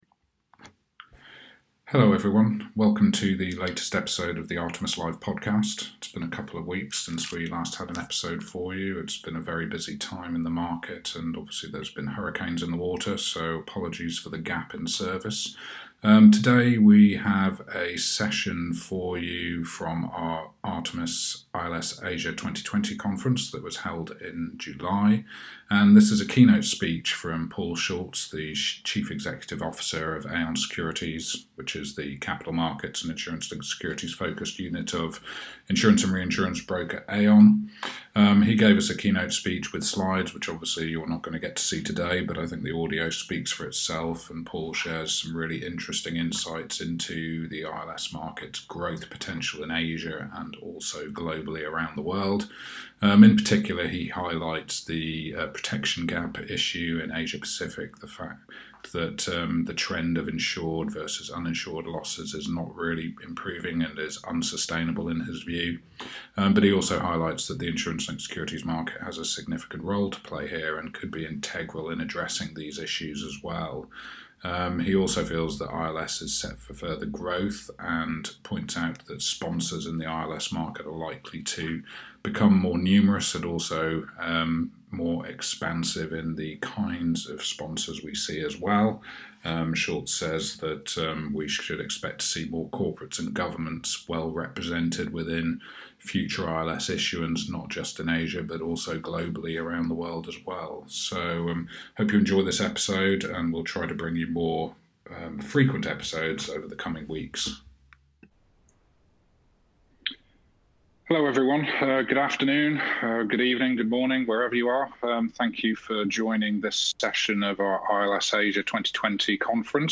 ILS Asia 2020 keynote